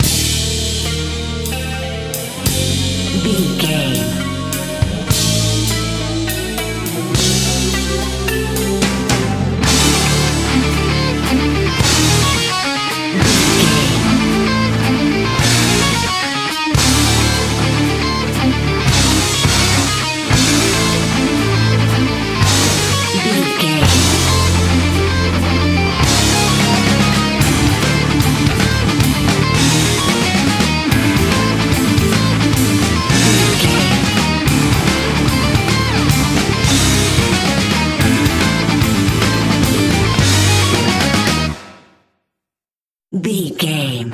Aeolian/Minor
drums
electric guitar
bass guitar
pop rock
hard rock
metal
lead guitar
aggressive
energetic
intense
powerful
nu metal
alternative metal